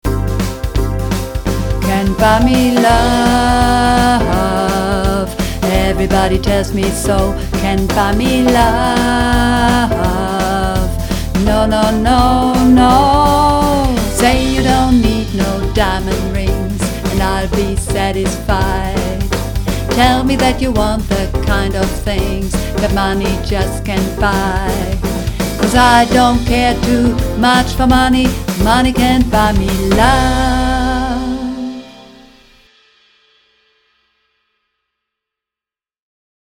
Übungsaufnahmen - Can't Buy Me Love
Runterladen (Mit rechter Maustaste anklicken, Menübefehl auswählen)   Can't Buy Me Love (Bass)